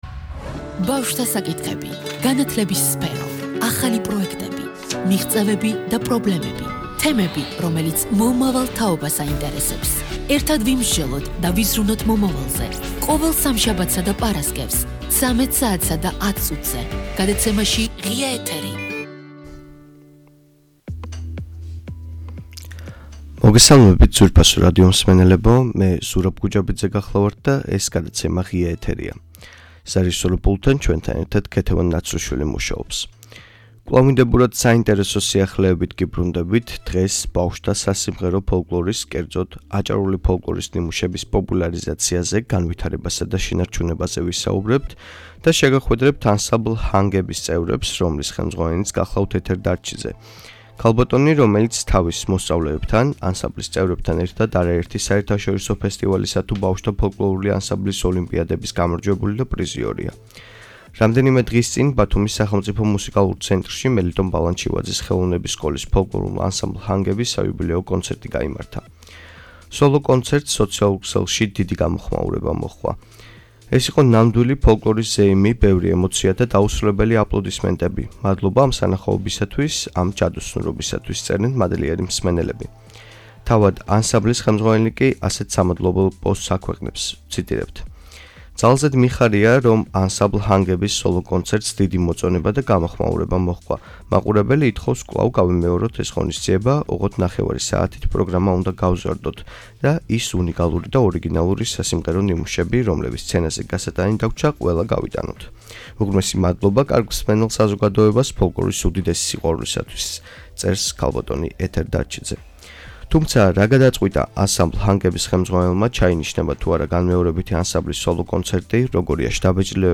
ბავშვთა სასიმღერო ფოლკლორი, ანსამბლ ,,ჰანგების’’ საიუბილეო კონცერტი ,არსებობის 10 წლიანი ისტორია, თაობათა ცვლა და აჭარული ფოლკლორის პოპულარიზაცია.